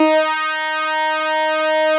Added synth instrument
snes_synth_051.wav